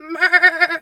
sheep_2_baa_09.wav